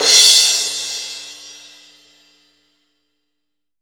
MG CRASH-3-S.WAV